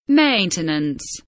maintenance kelimesinin anlamı, resimli anlatımı ve sesli okunuşu